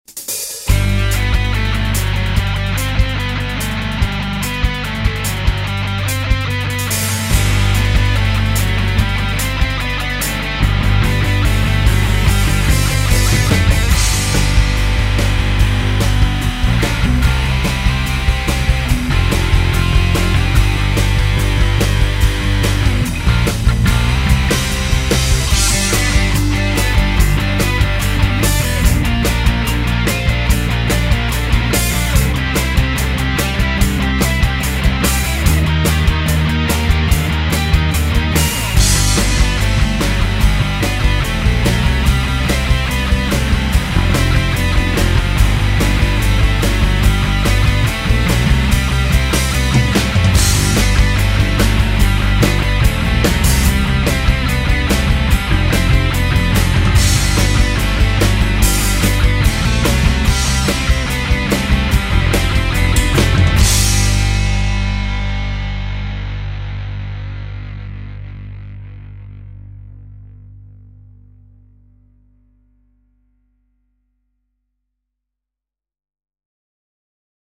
JAZZ AND LATIN